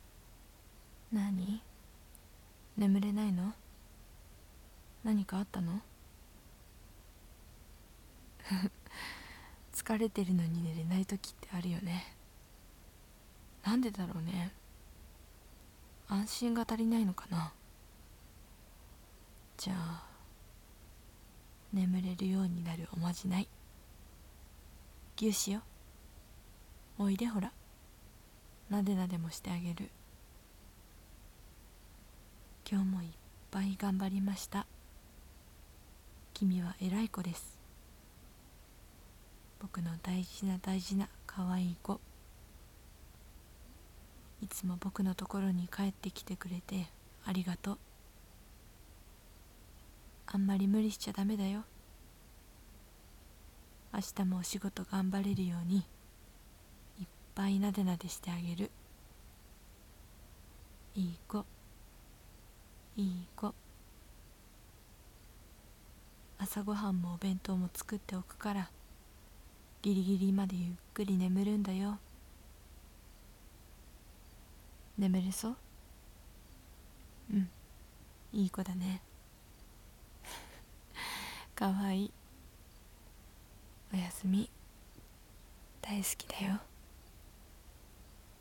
シチュエーションボイス 「おやすみ」